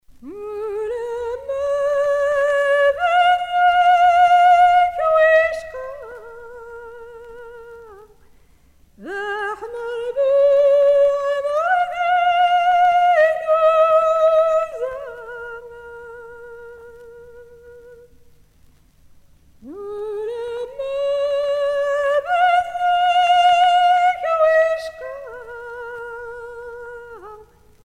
Chant du berceau
berceuse